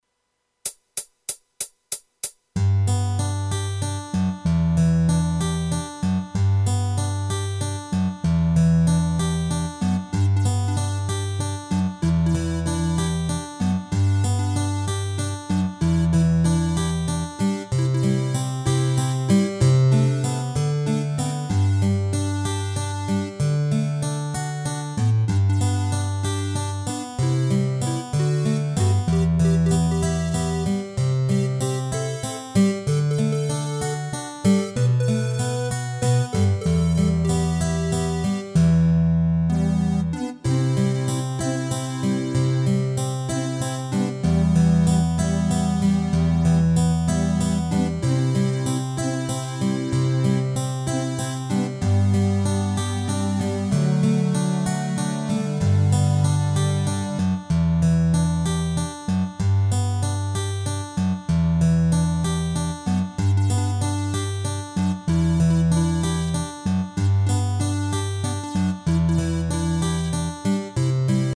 Rubrika: Pop, rock, beat
HUDEBNÍ PODKLADY V AUDIO A VIDEO SOUBORECH